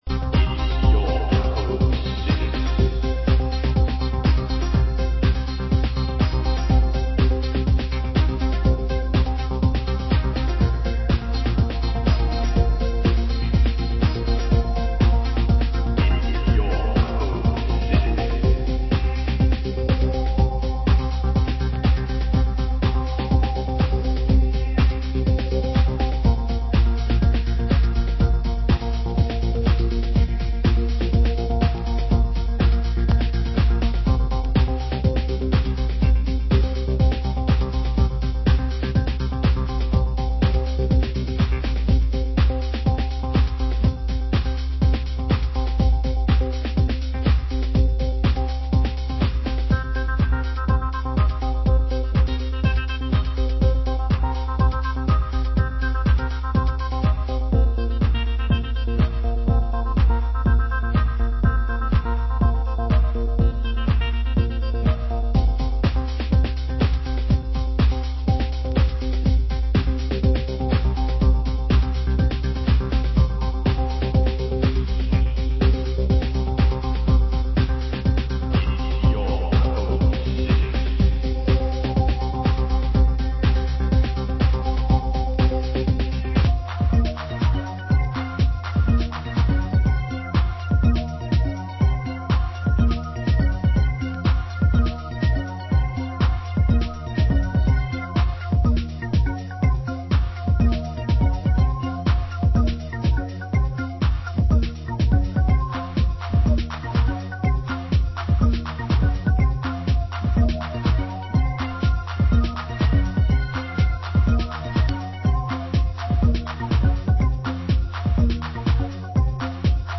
Genre: US Techno